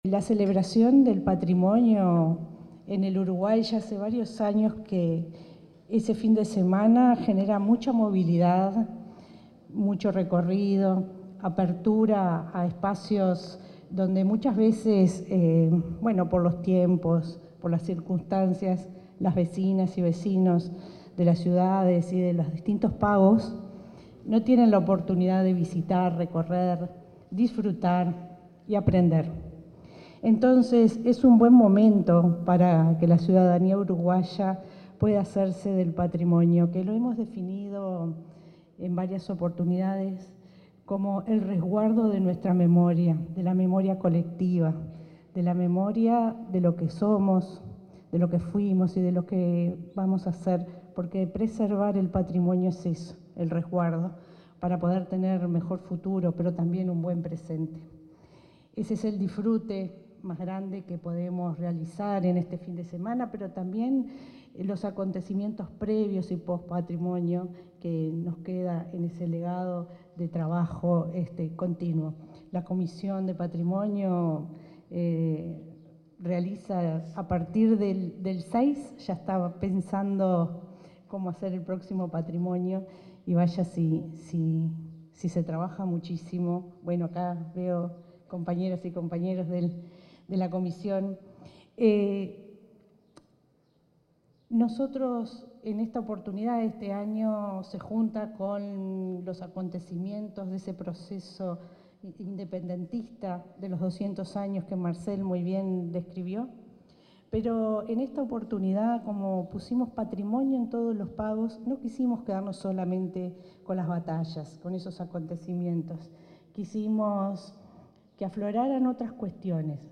Palabras de la ministra interina de Educación y Cultura, Gabriela Verde